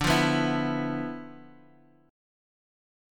Listen to Dm6add9 strummed